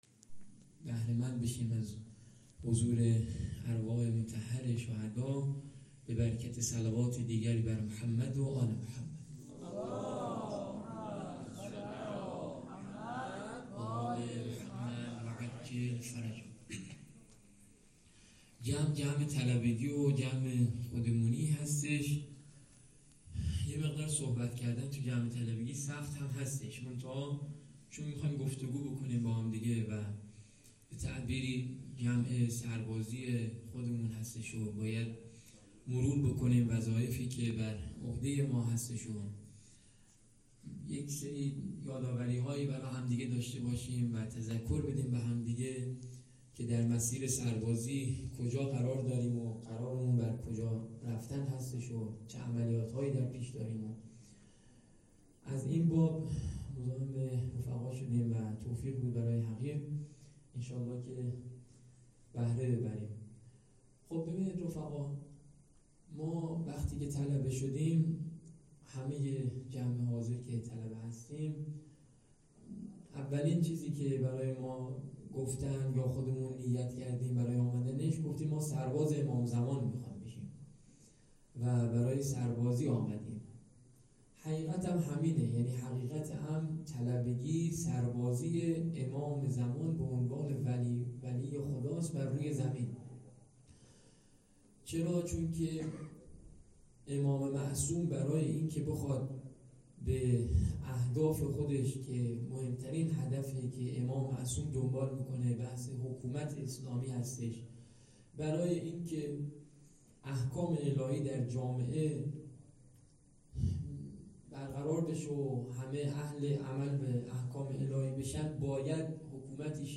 سخنرانی
جلسه هفتگی 1401 هیئت خدام الرضا مدرسه علمیه رضویه